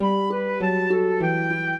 flute-harp
minuet9-7.wav